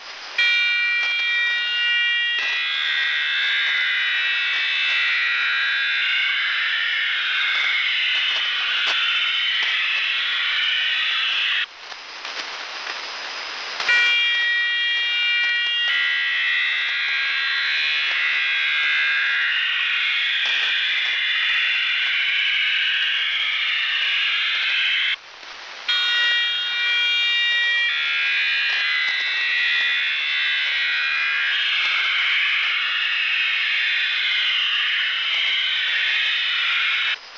Начало » Записи » Радиоcигналы классифицированные
Запись израильской передачи OFDM